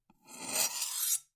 Слушайте онлайн или скачивайте бесплатно резкие, металлические скрежеты и ритмичные движения точильного камня.
Звук Короткий звук заточки ножа (одиночный) (00:01)